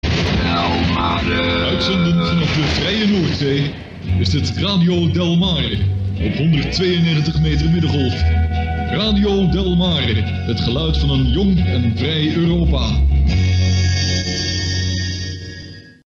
Bekende Tune https